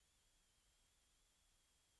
When the AIC3104 is configured in differential input mode, the noise can be heard.
(Please increase volume level when hearing noise)
This noise can be heard even if the input channnel is connected with GND via a capacitor and this can not be heard in single-ended mode.